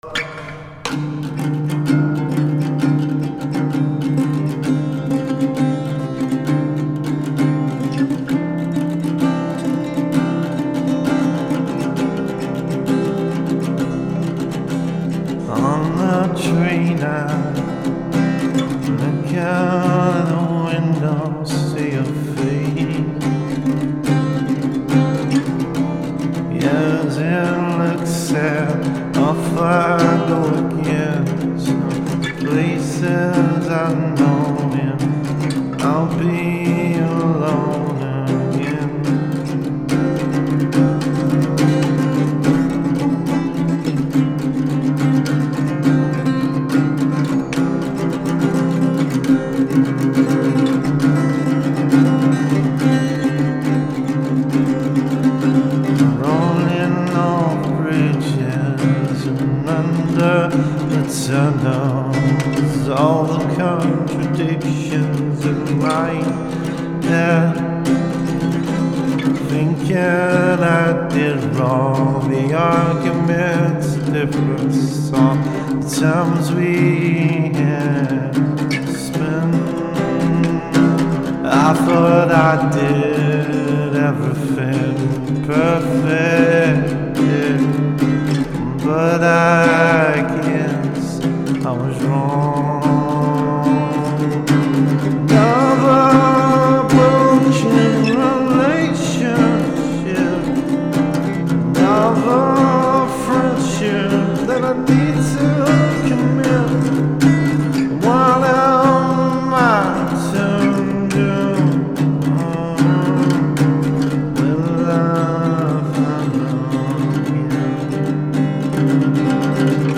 vocals guitars